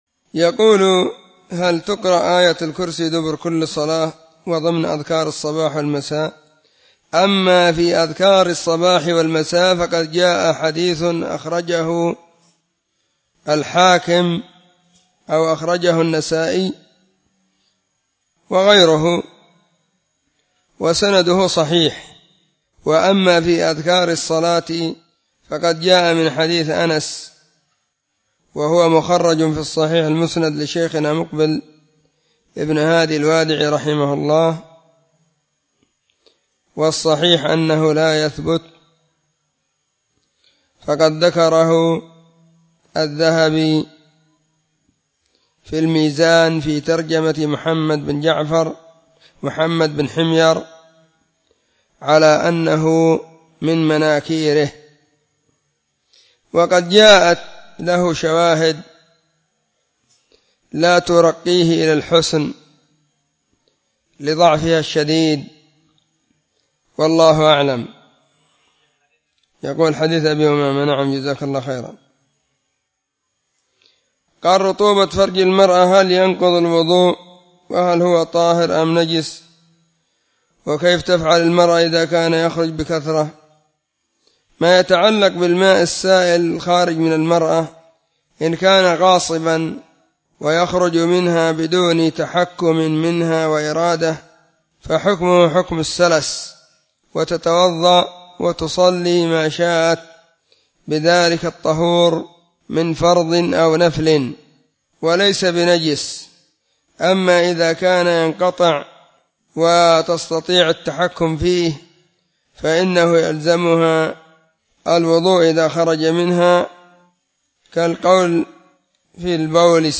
🔊 فتاوى..
سلسلة الفتاوى الصوتية